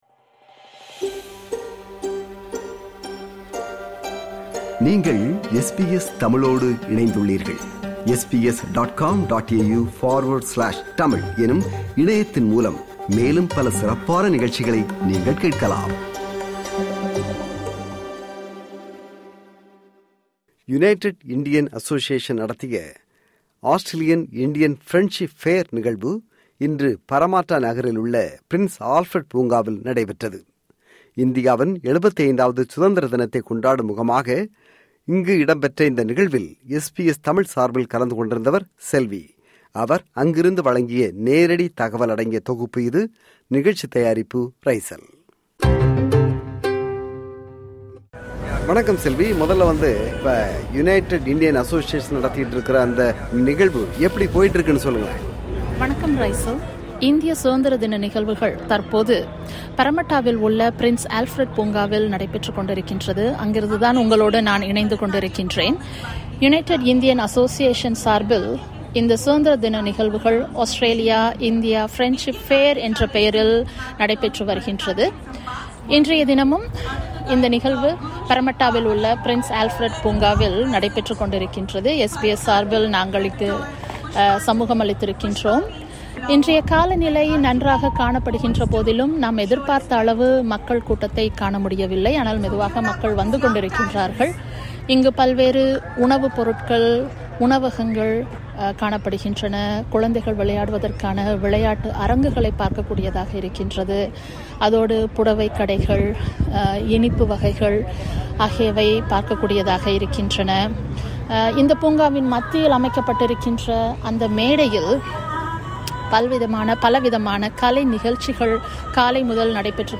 Its is a celebration of Indian Independence Day. This program brings details live from the event